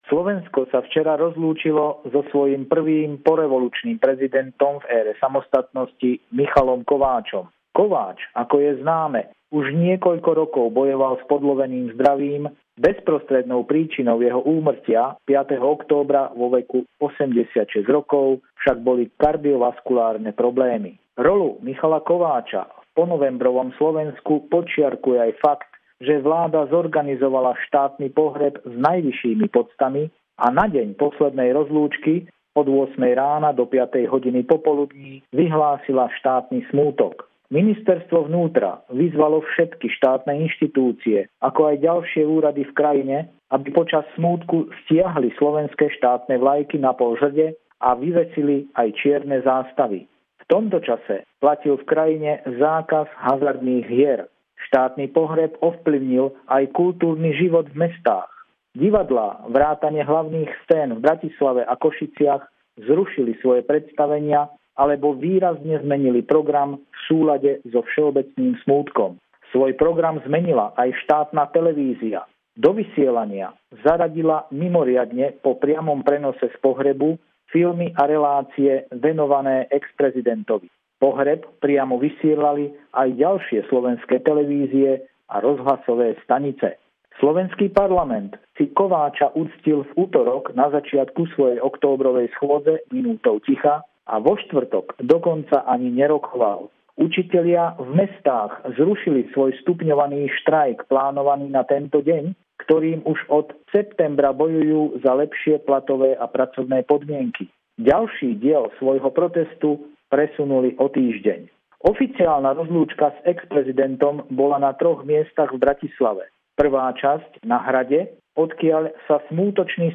Pravidelná zvuková pohľadnica